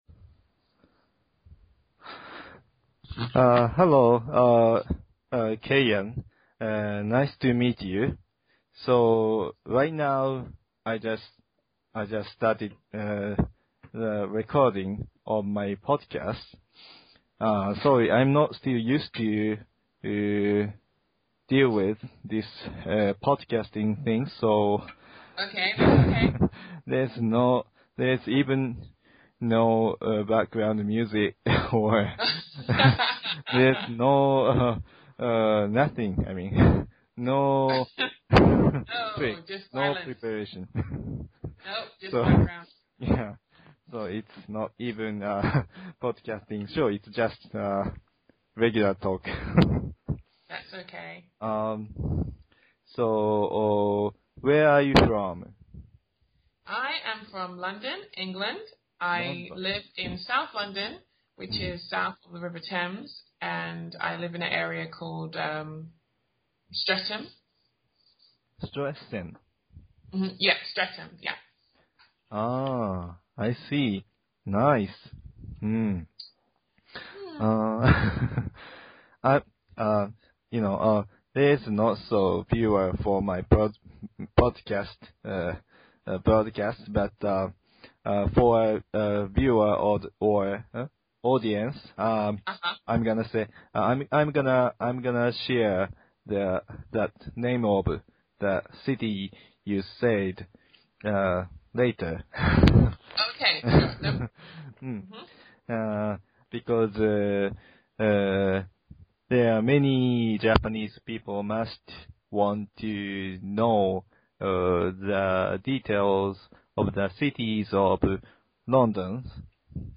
Today we have a special guest from UK.